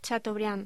Locución: Chateaubriand